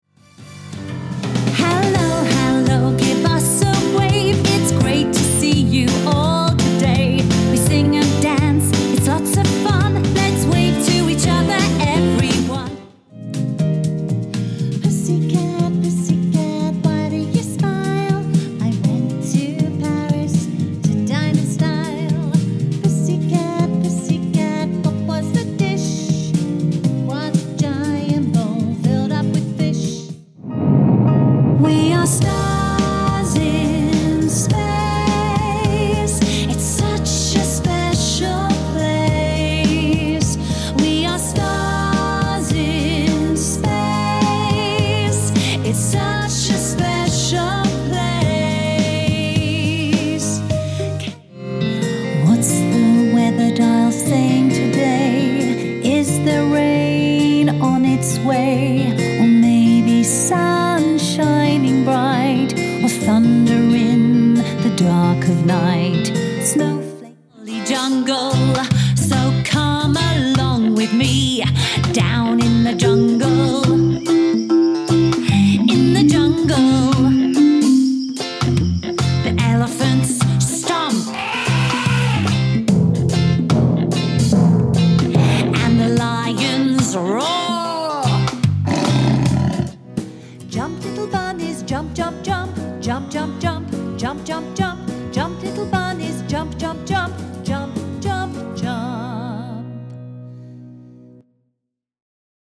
With my clear, bright British tones, I bring a touch of class to every project, ensuring your message resonates with listeners everywhere.
Words that describe my voice are British, Friendly, Natural.
0811KIDS_DEMO_REEL.mp3